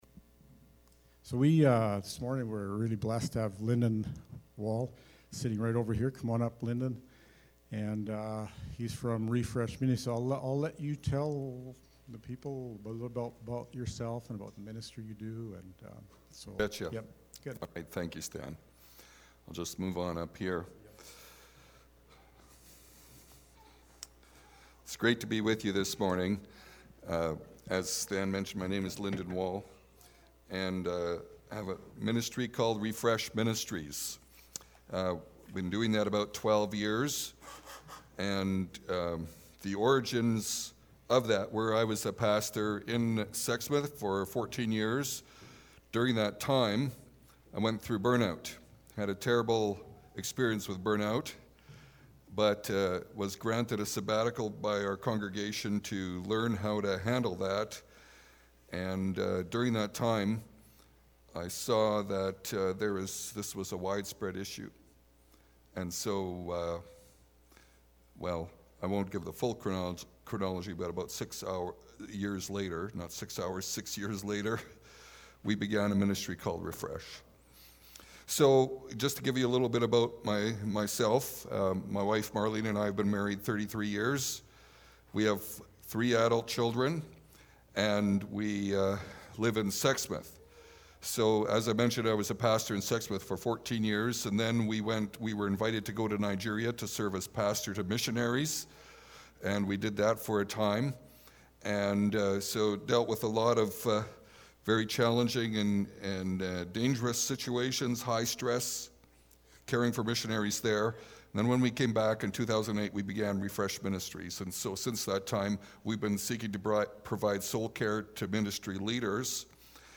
nov-8-2020-sermon.mp3